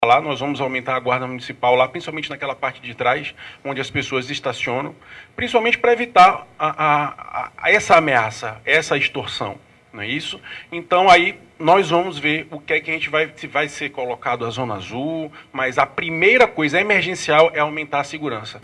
O secretário municipal de Segurança e Ordem Pública (Semseg), Alberto de Siqueira Neto, também participou da coletiva e informou que a prefeitura tem mapeado áreas críticas de estacionamento irregular, como o centro de Manaus e o bairro Ponta Negra.